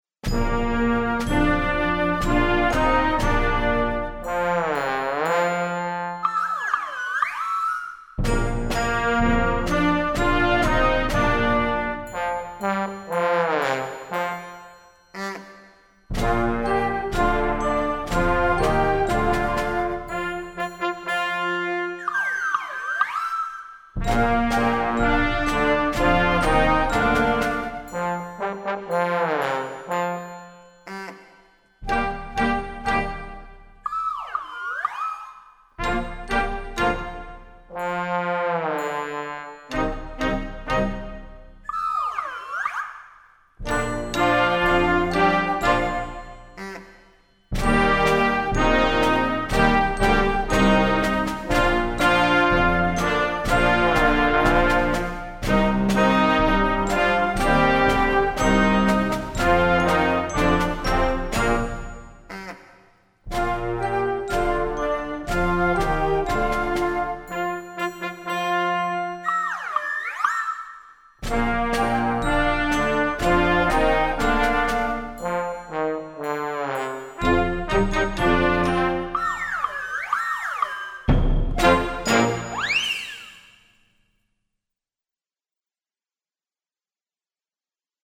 Voicing: Trombone Section w/ Band